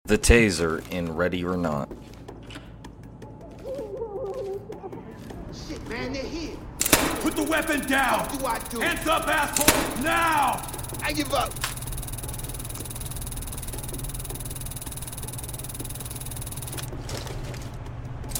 The Tazer In ready or sound effects free download